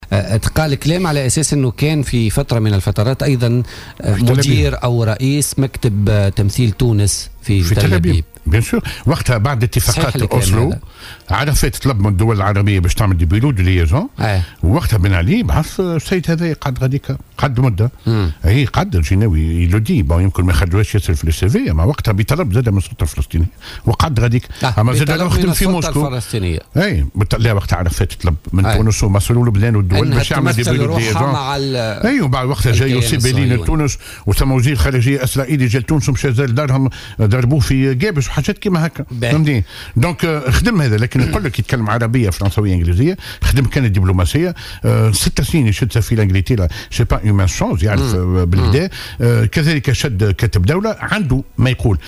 في حوار مع الجوهرة أف أم